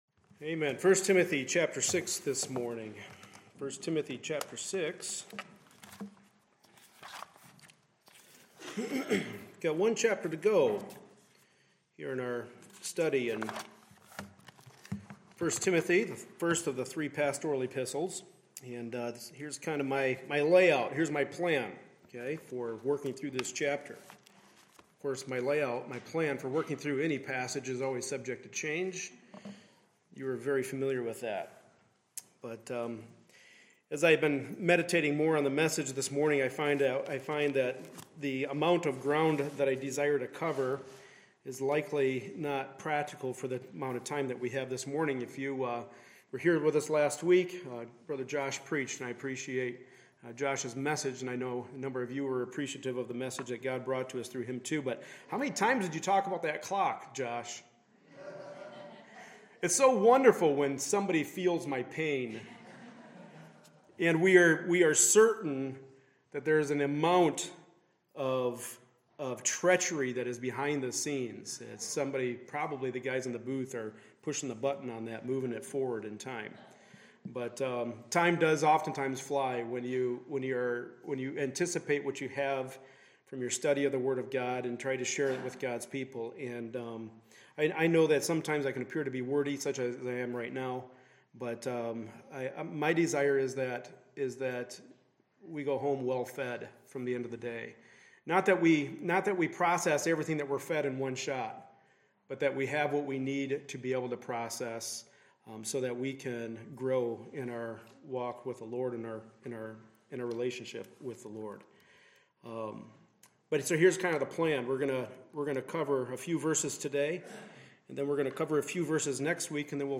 Passage: 1 Timothy 6:3-11 Service Type: Sunday Morning Service